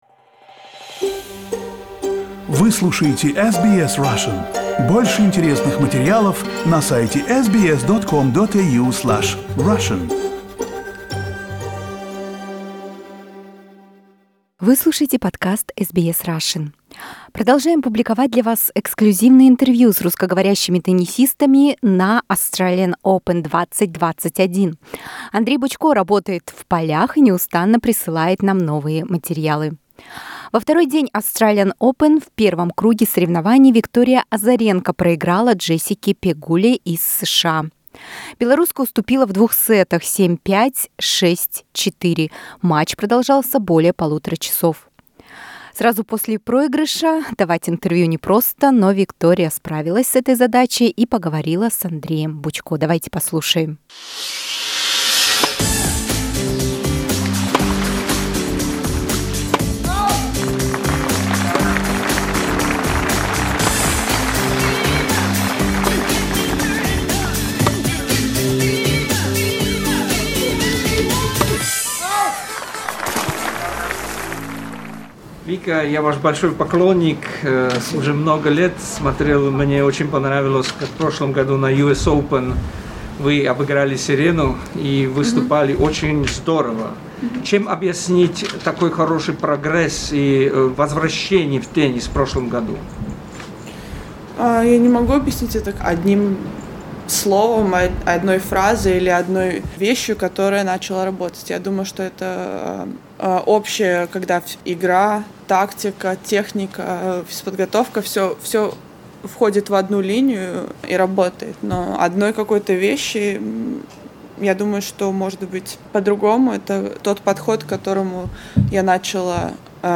Interview with Belarusian tennis player Victoria Azarenka.